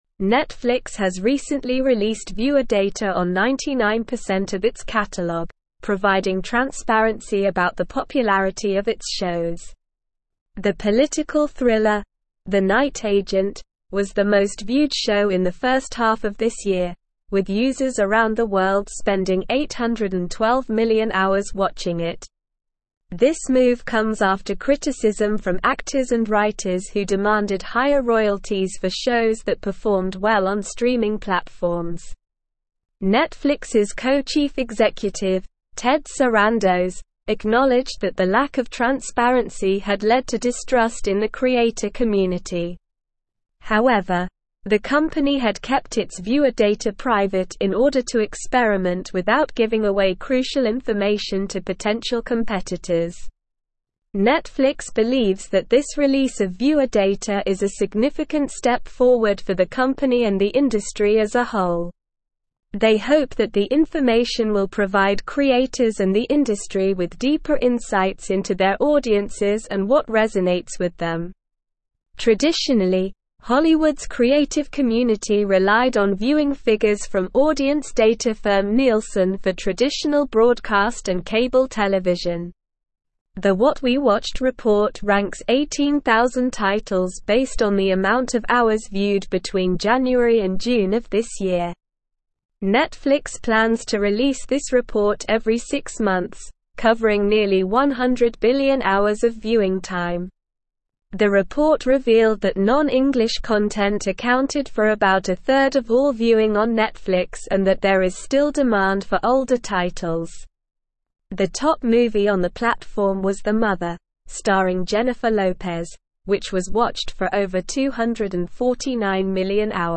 Slow
English-Newsroom-Advanced-SLOW-Reading-Netflix-Reveals-Most-Watched-Show-and-Movie-of-2022.mp3